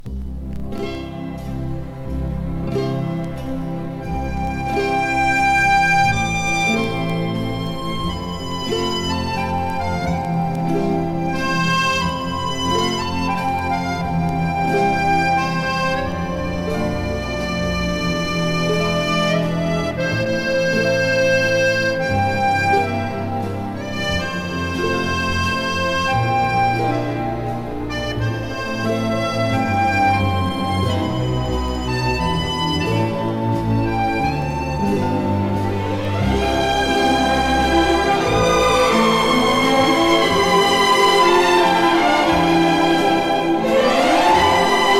耳馴染み良く、豊かに響く演奏に心動かされる良盤です。